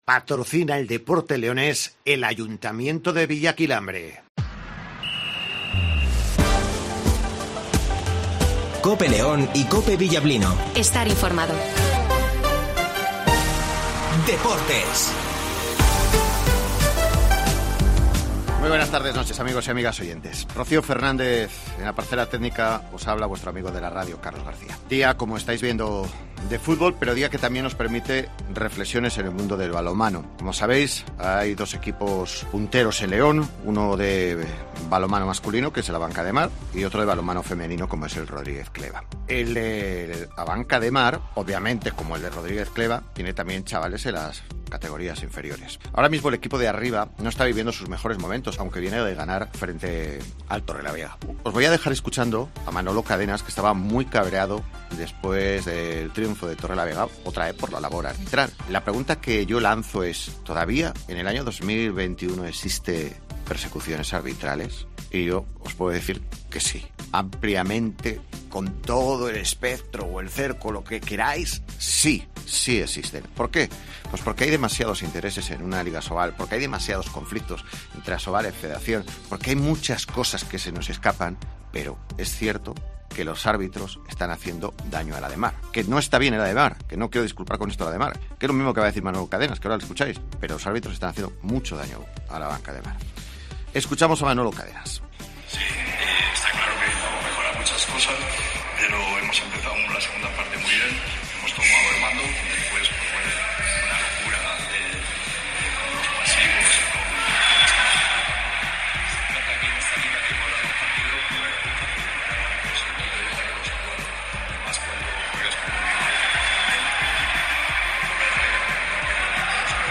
- Manolo Cadenas ( Entrenador del " Abanca Ademar " )